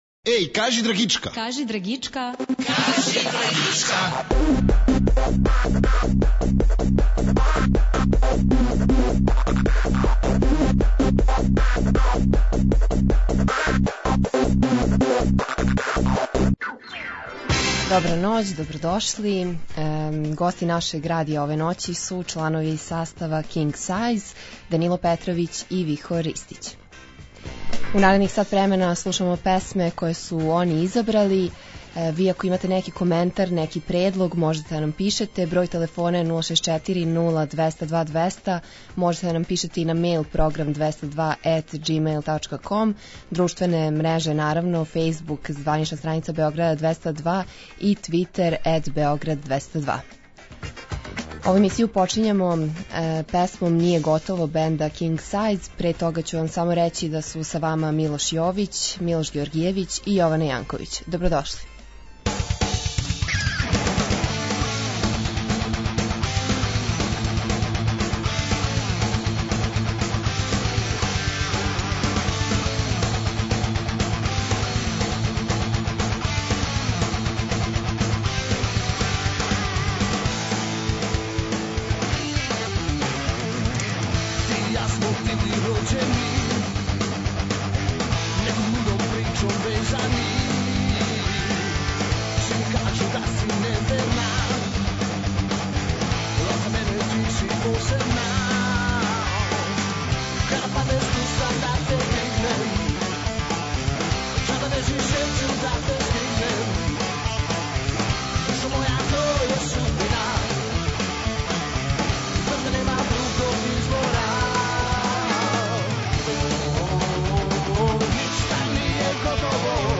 Гости нашег радија су чланови рок састава King Size!